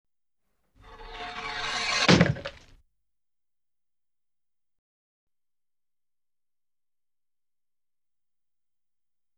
Scary Sounds - 33 - Guillotine Type